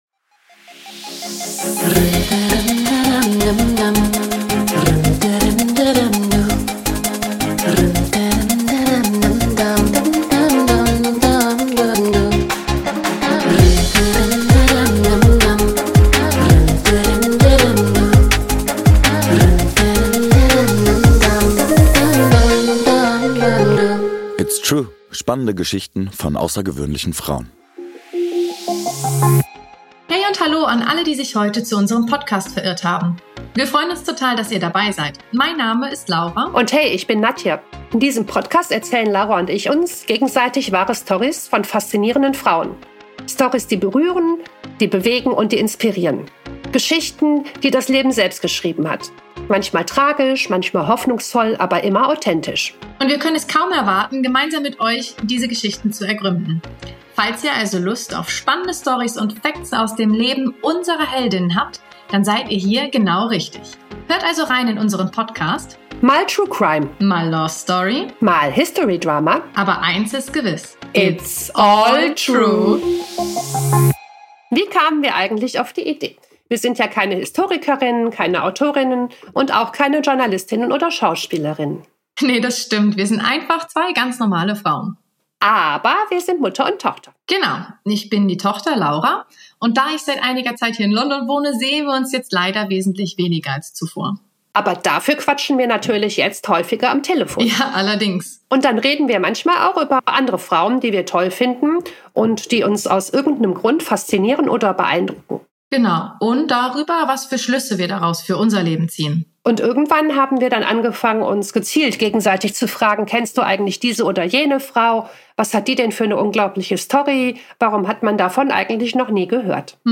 Mit persönlichen Einblicken, historischen Sidefacts und einem Blick auf Hildegards Heilkunde, Musik und Weltbild.  Und am Ende: Wie immer das Mutter-Tochter-Gespräch – ehrlich, neugierig, berührend.